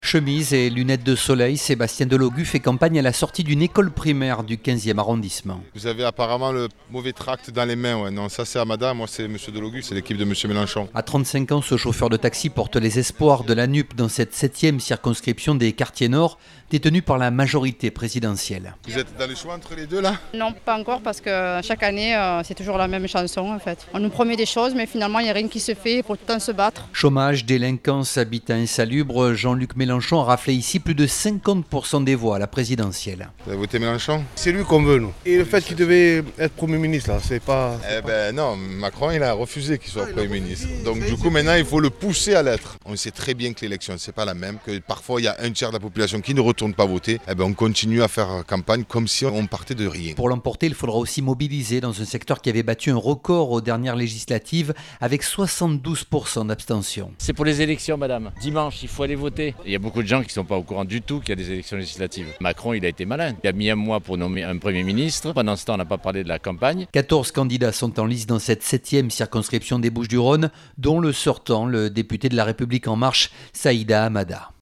Législatives : Marseille enverra-t-elle plusieurs députés de la NUPES à l’Assemblée nationale ? Reportage
Chemise et lunettes de soleil, Sébastien Delogu fait campagne à la sortie d’une école primaire du 15e arrondissement de Marseille.